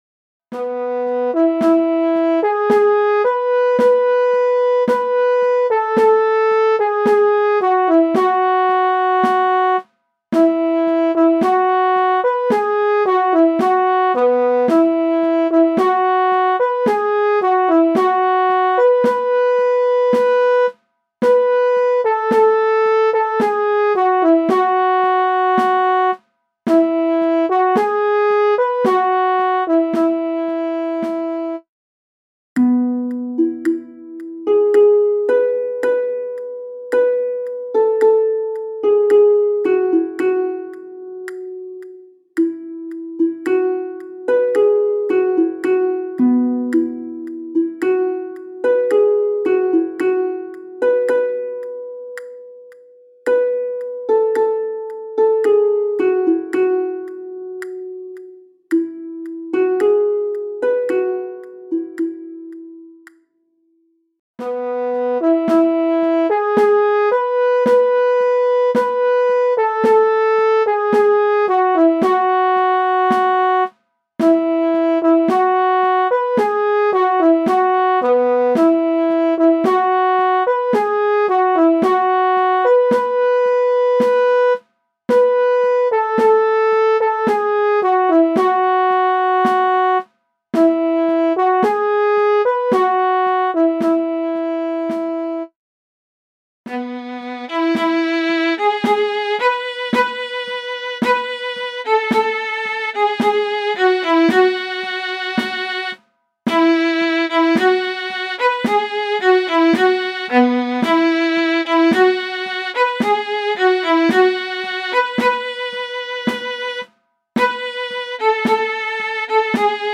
MIDI von 1984 [6.375 KB] - mp3